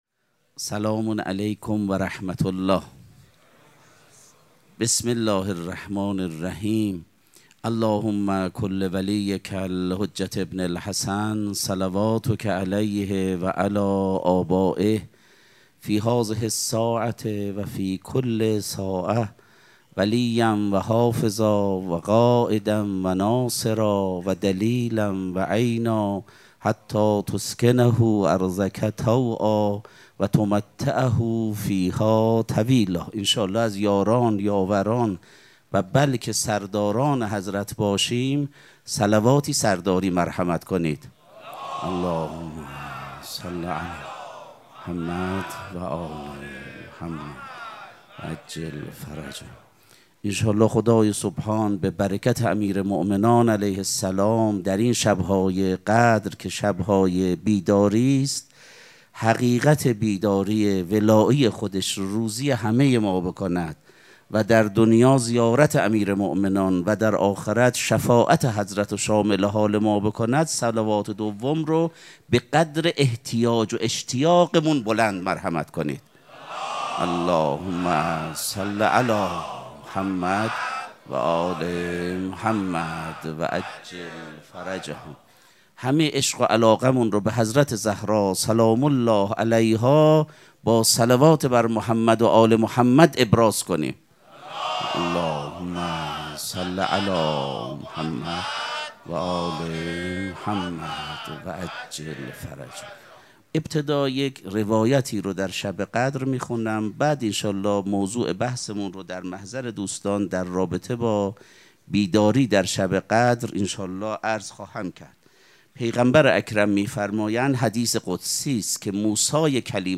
سخنرانی
مراسم مناجات شب نوزدهم ماه مبارک رمضان چهارشنبه‌‌ ۲۹ اسفند ماه ۱۴۰۳ | ۱۸ رمضان ۱۴۴۶ حسینیه ریحانه الحسین سلام الله علیها